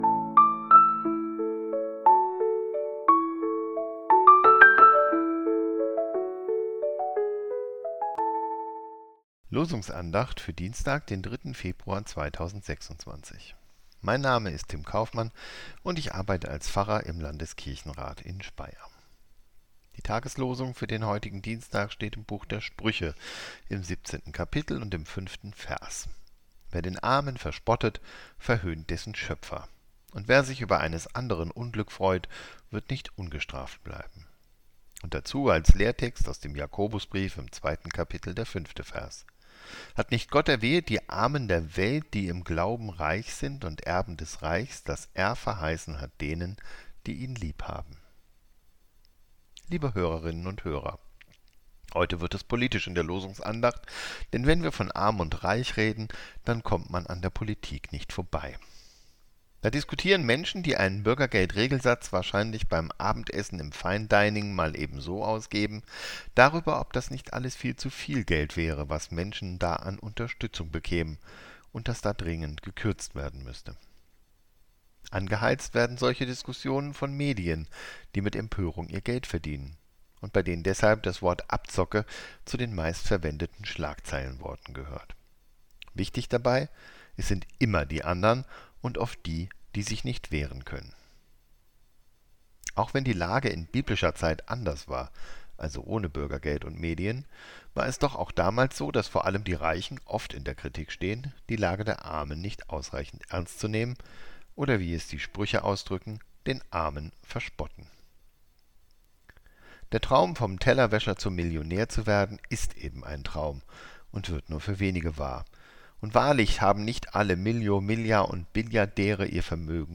Losungsandacht für Dienstag, 03.02.2026